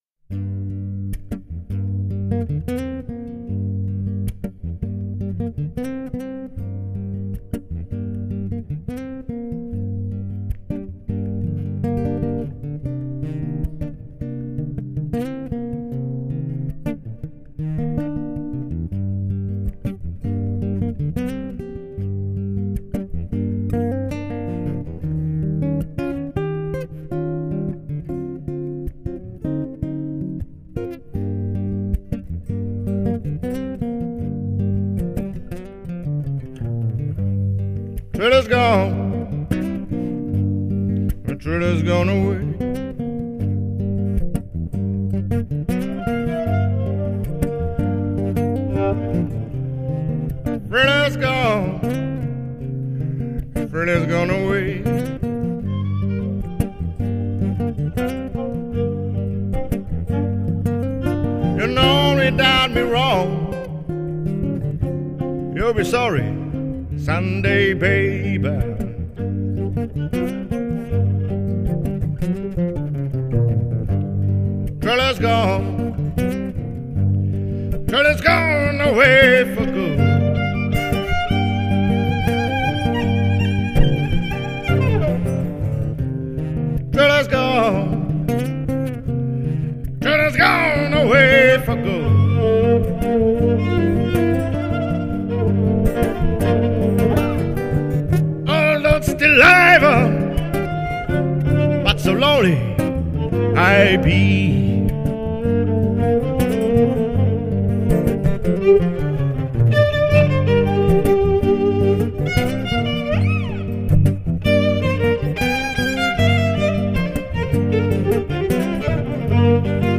violin
vocal, guitar, dobro, slide
guitar, harmonica, vocal